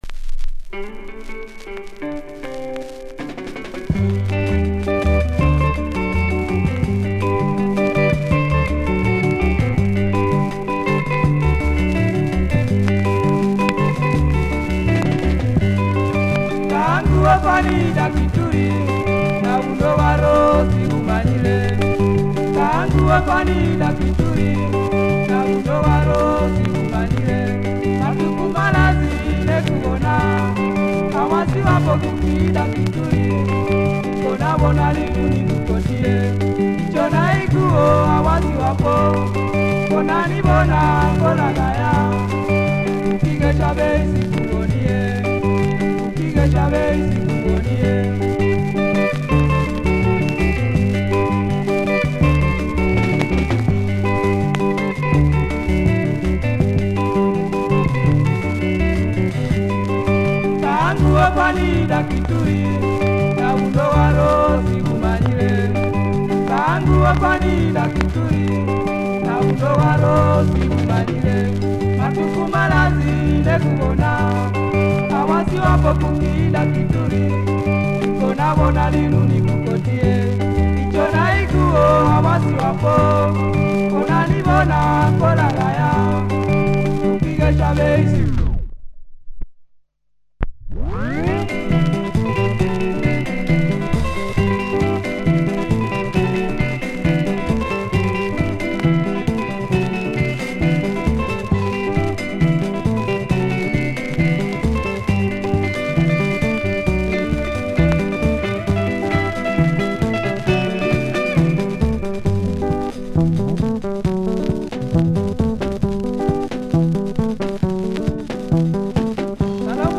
Check audiosnippet for both sides.